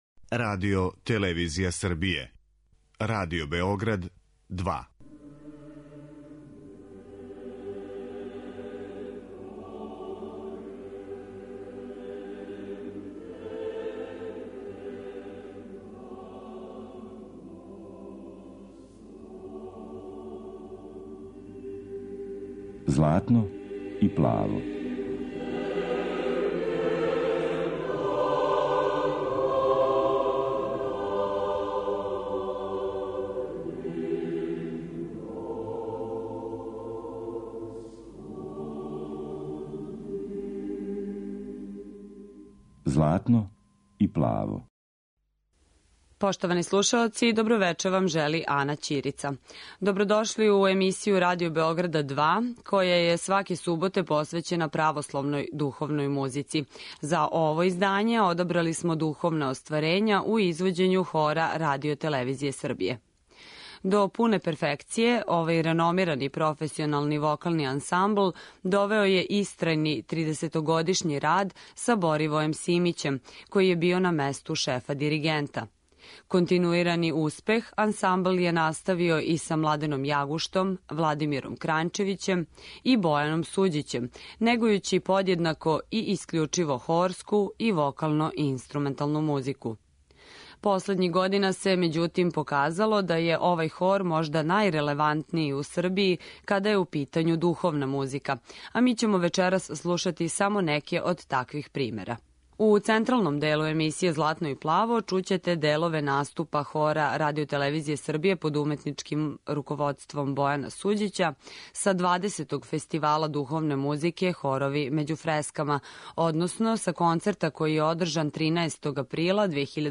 Емисија посвећена православној духовној музици